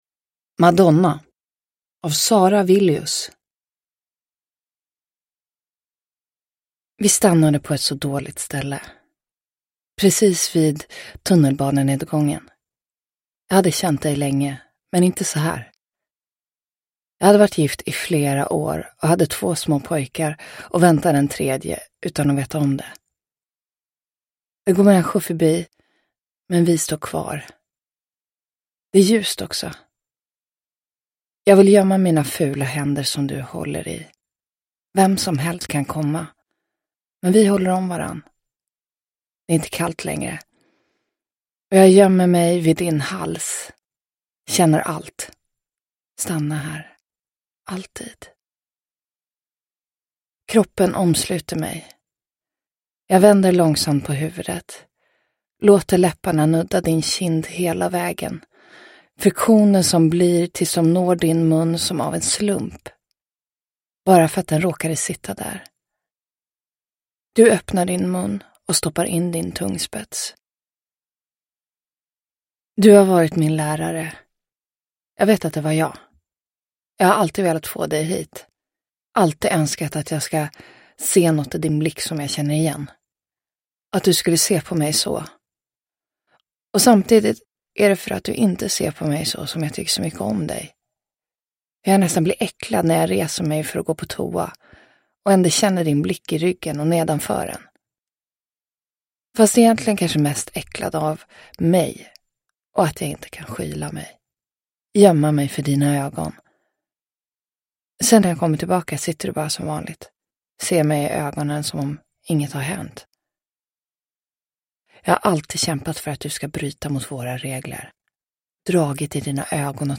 Madonna – Ljudbok – Laddas ner
Uppläsare: Liv Mjönes